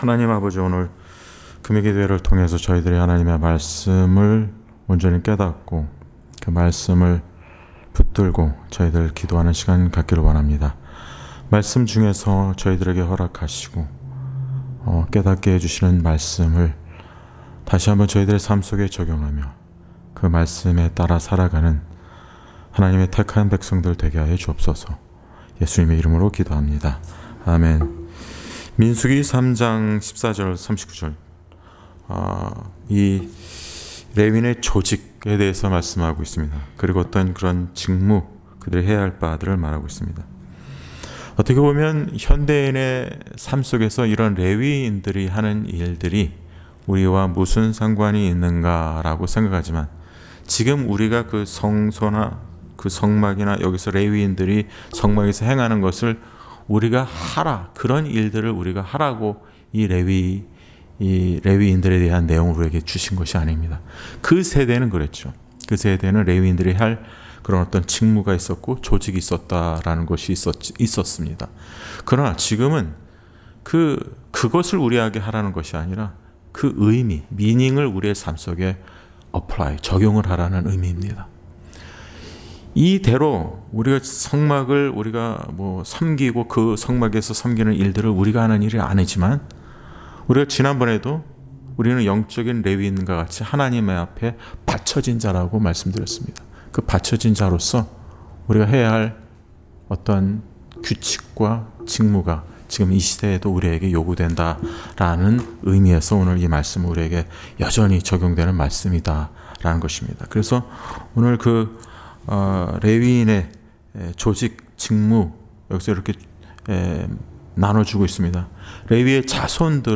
Series: 금요기도회